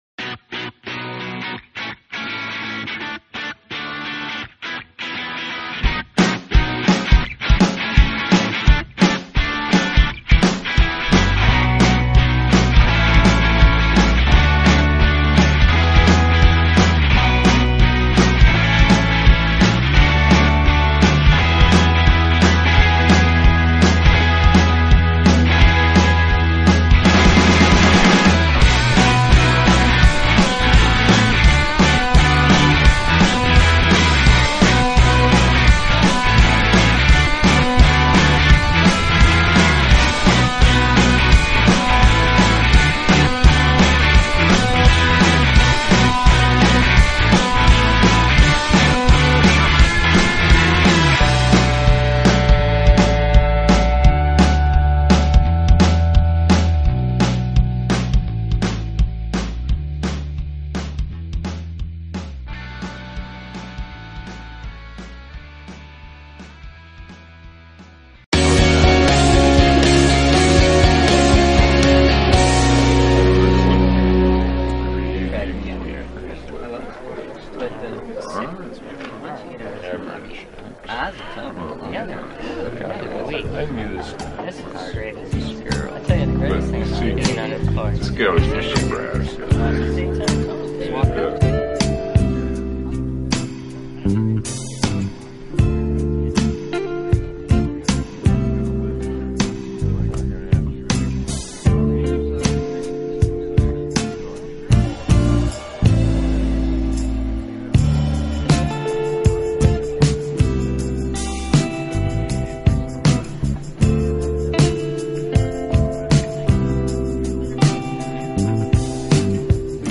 Rozmowy w Nocy to internetowy talk-show na żywo z udziałem słuchaczy w środy 23.00. Jest to audycja o życiu ludziach i ich problemach.